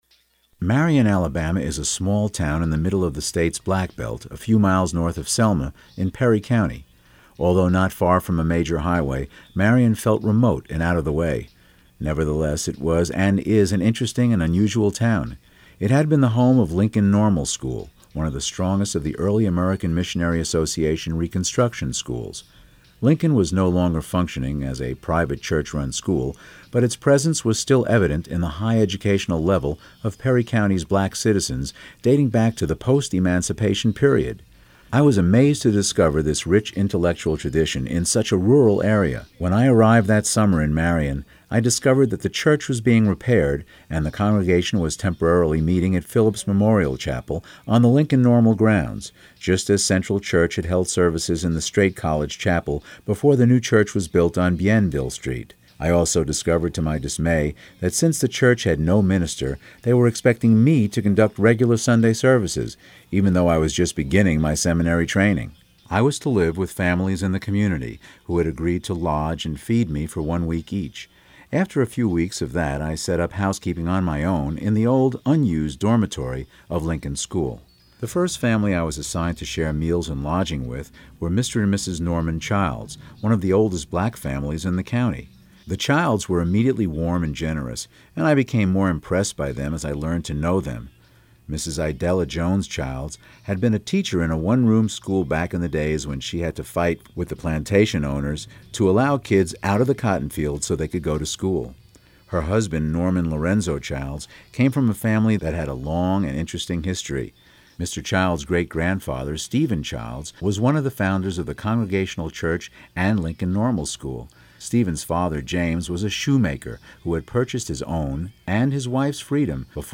Audio Book Demos
Male Voice Over Talent